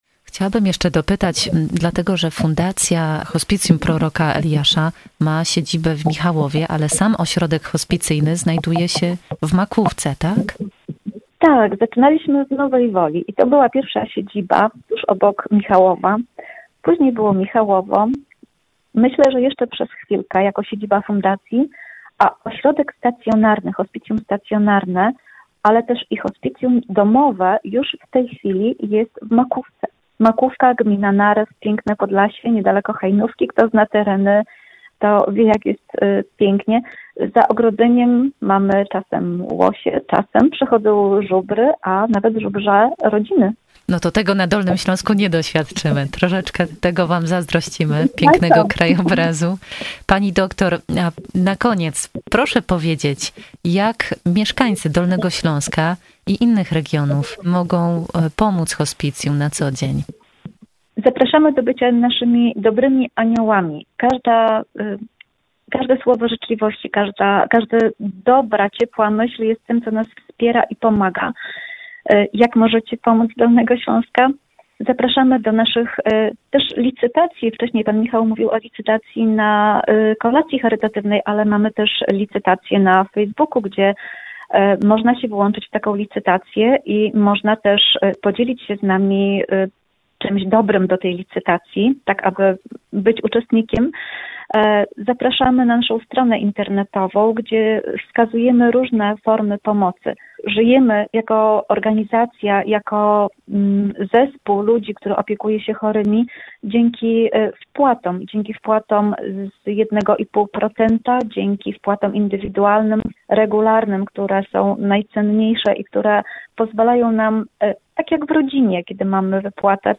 Więcej w rozmowie z naszymi gośćmi
03_Hospicjum-Proroka-Eliasza_rozmowa.mp3